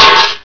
metal4.wav